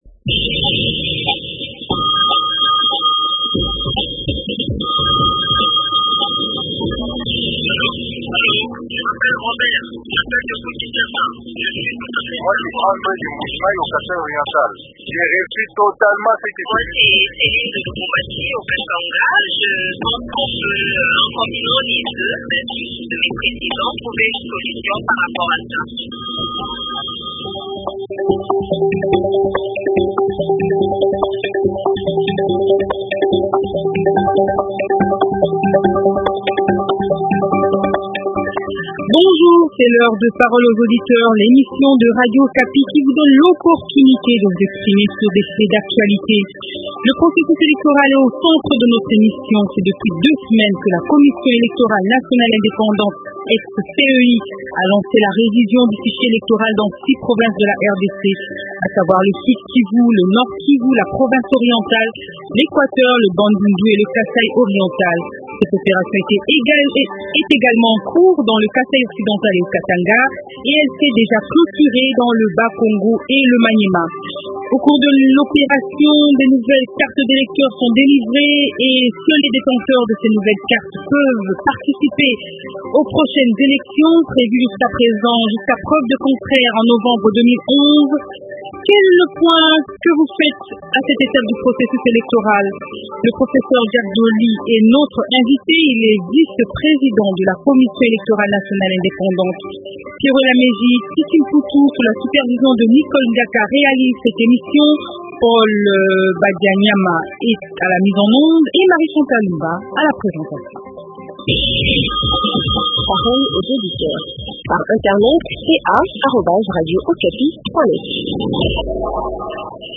Invité : Professeur Jacques Ndjoli, vice-président de la Ceni.